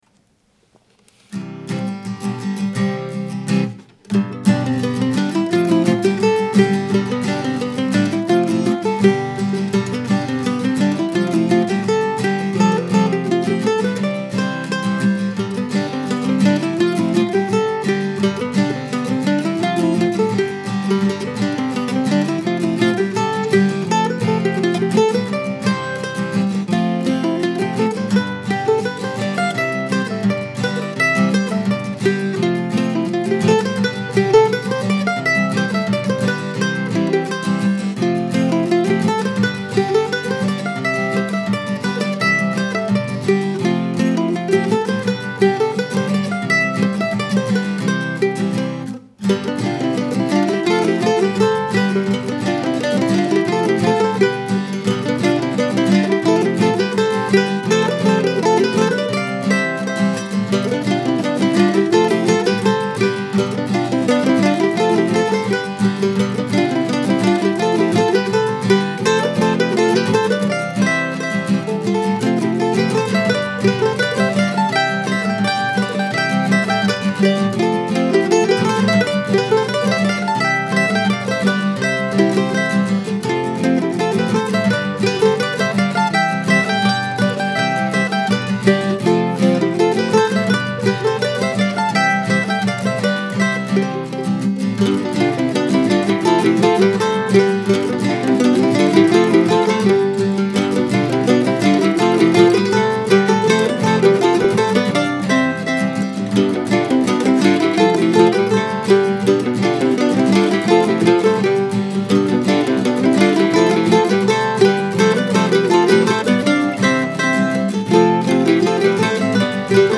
I've chosen to record it at a comfortable tempo rather than try to force it into a higher speed dance tune, although I know that it works pretty well that way too.